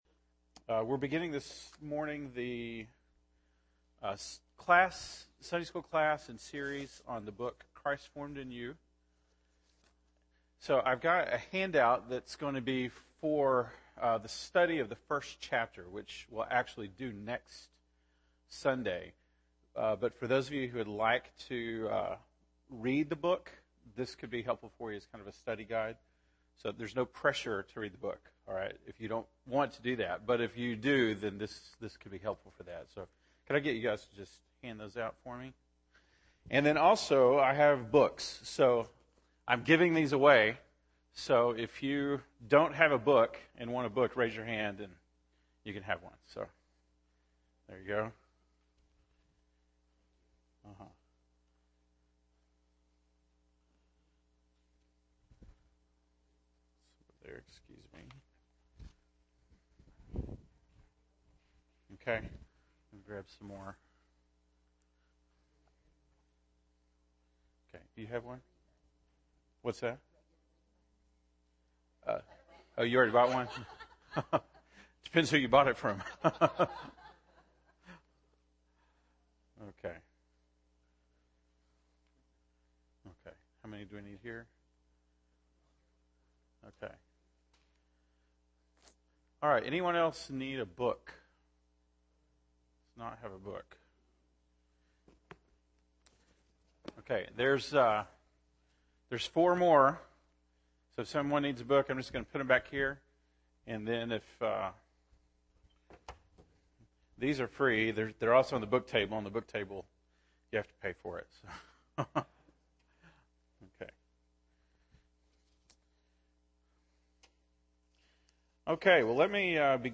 September 14, 2014 (Sunday School)